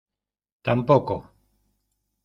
Devamını oku (İngilizce) neither; nor; also not either (as well, with implied negative) Sıklık A1 Tireli olarak tam‧po‧co (IPA) olarak telaffuz edilir /tamˈpoko/ Etimoloji (İngilizce) Univerbation of tan + poco.